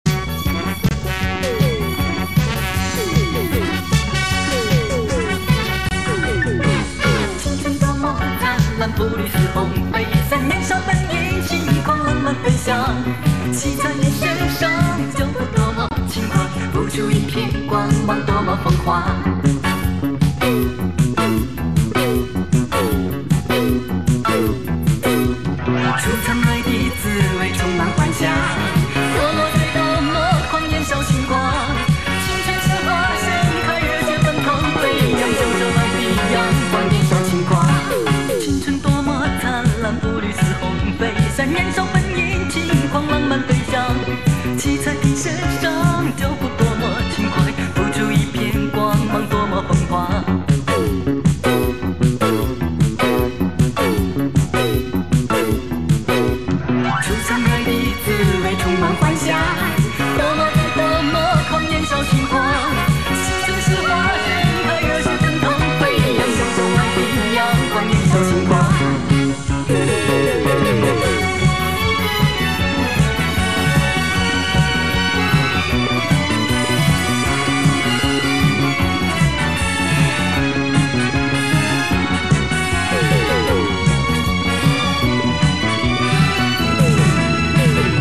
品质:TAPE-320KBPS